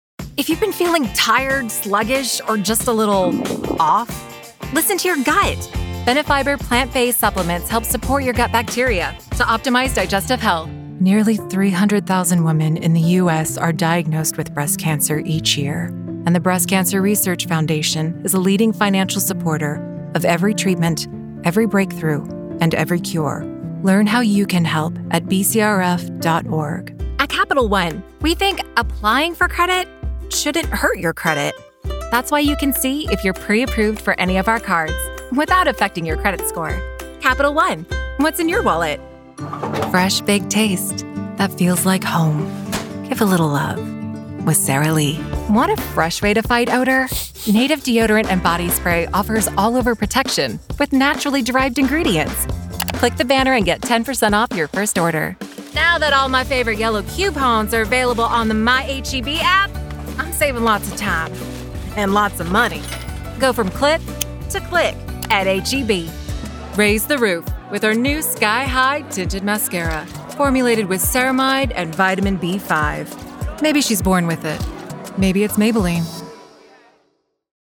Commercial Demo
English(American Neutral), Mid-Atlantic, Irish, Southern American General